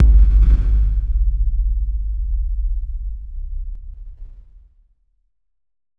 Low End 14.wav